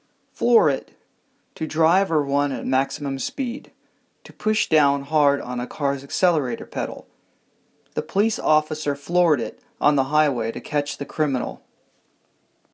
マンツーマンのレッスン担当の英語ネイティブによる発音は下記のリンクをクリックしてください。